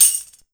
150JAMTAMB-L.wav